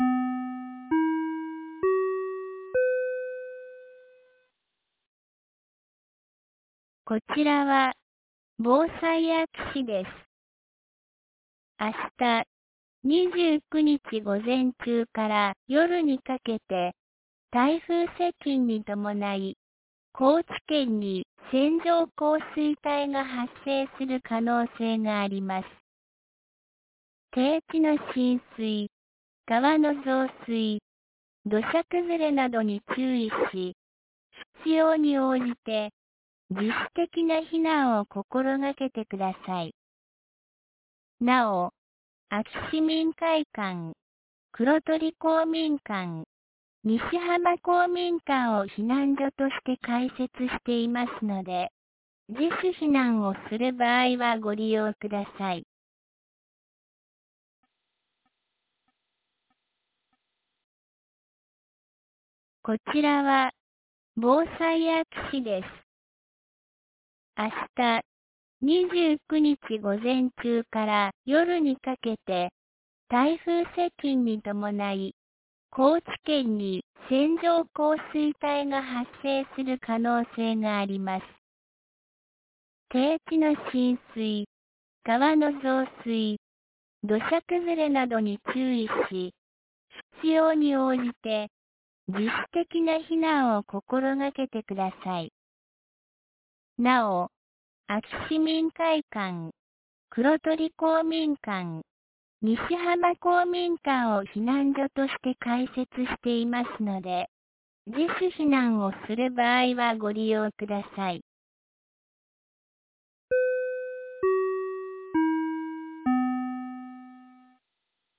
2024年08月28日 16時02分に、安芸市より安芸へ放送がありました。